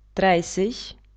dreißig [drajsich]